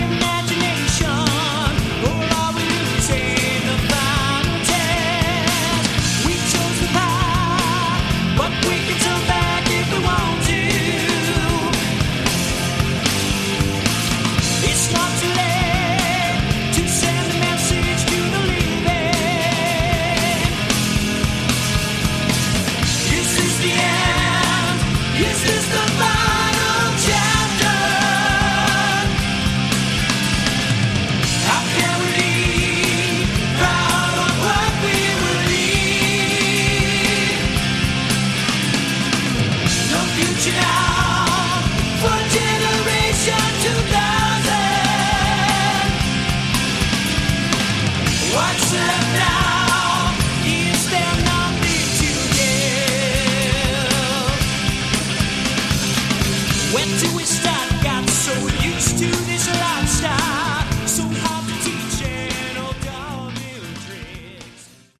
Category: AOR
lead vocals, guitar
lead and rhythm guitar, backing vocals
drums
additional keyboards